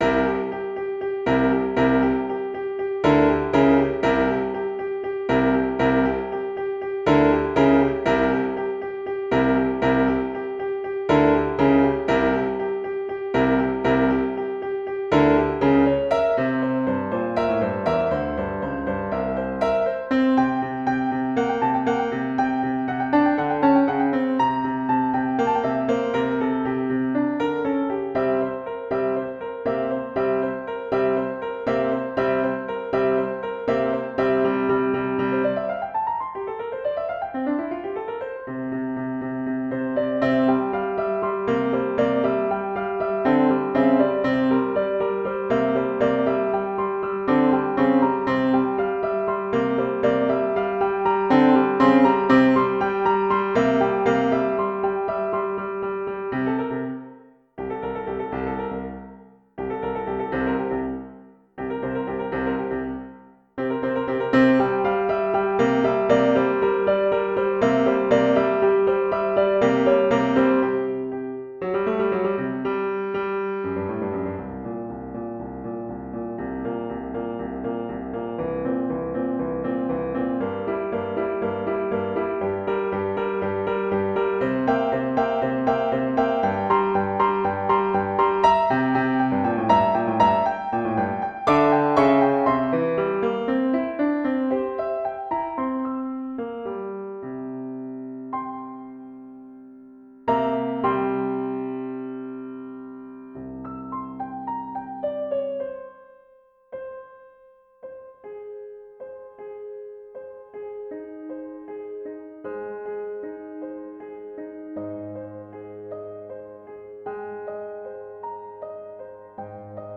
Audio: Piano part alone